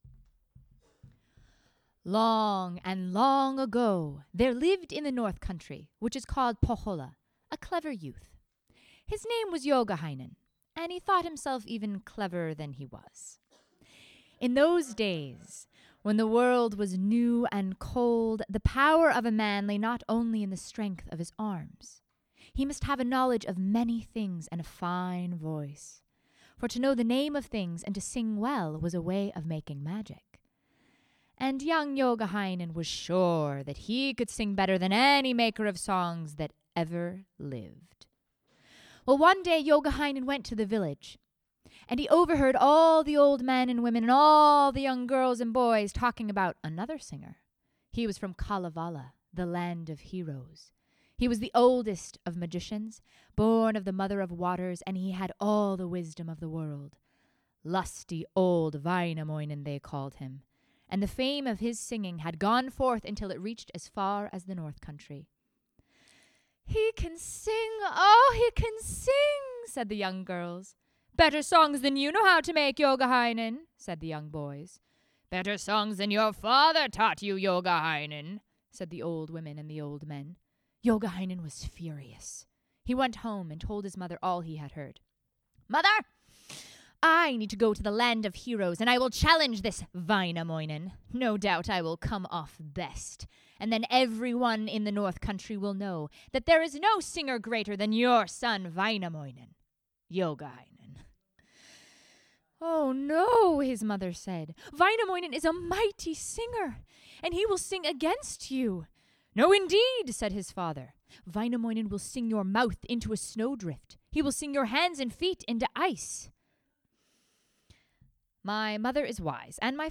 Stories from the Finnish Epic: The Kalevala as told at the Seattle Storytellers Guild Epic Event at the Nordic Heritage Museum on March 25th, 2017.